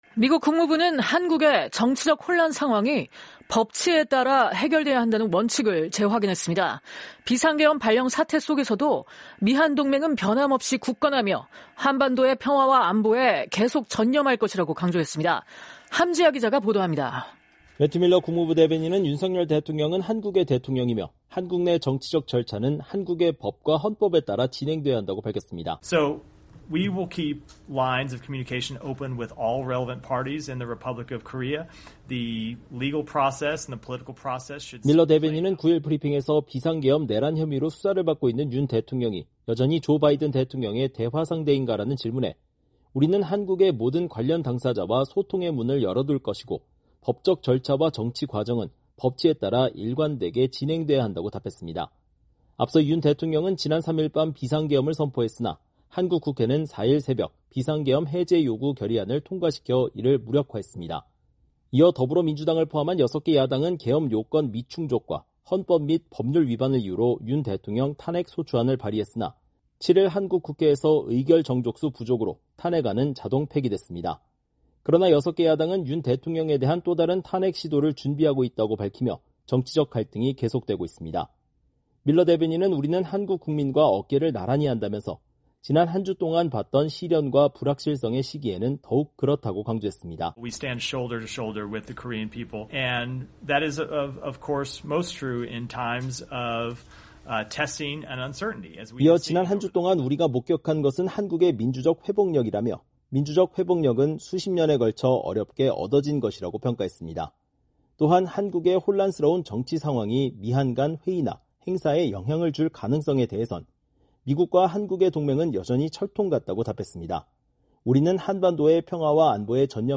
매튜 밀러 국무부 대변인이 9일 브리핑을 하고 있다.